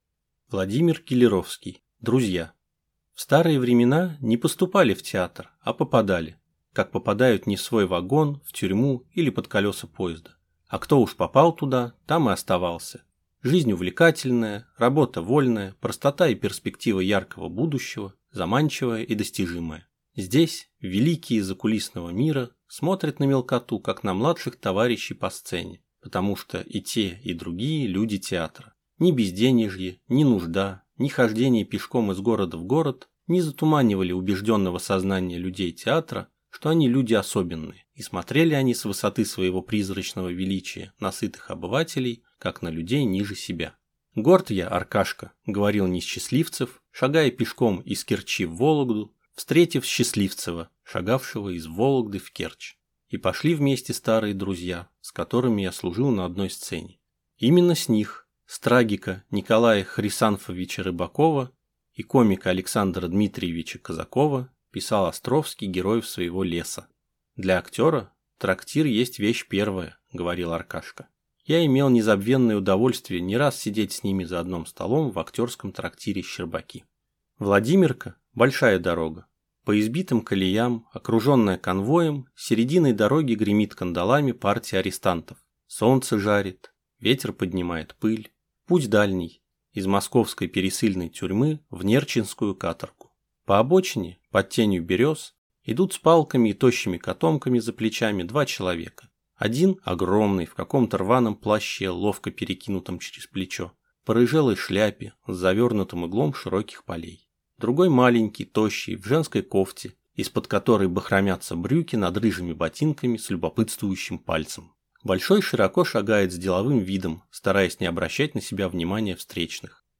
Аудиокнига Друзья | Библиотека аудиокниг